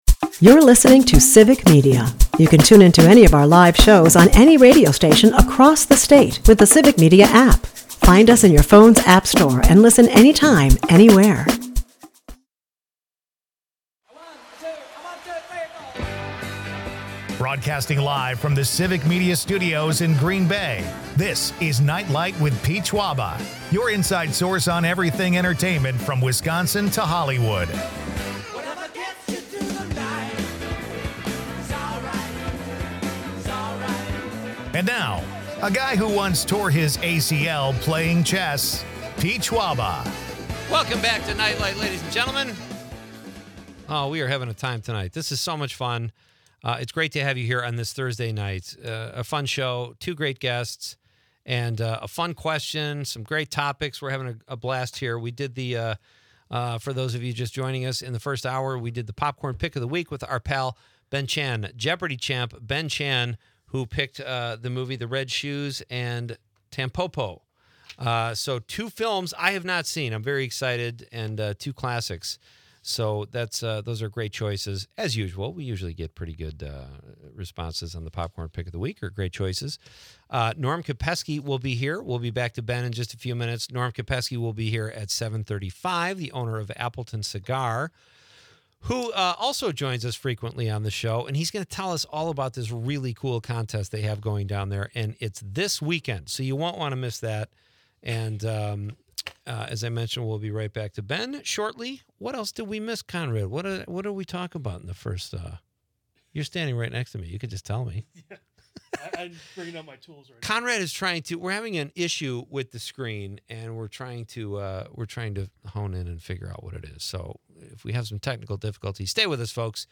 Listeners participate in a spirited debate on the saddest songs ever, suggesting tracks like 'Tears in Heaven' and 'Danny Boy.' The show also features a text-to-win contest for cash and Green Bay football tickets, adding to the excitement.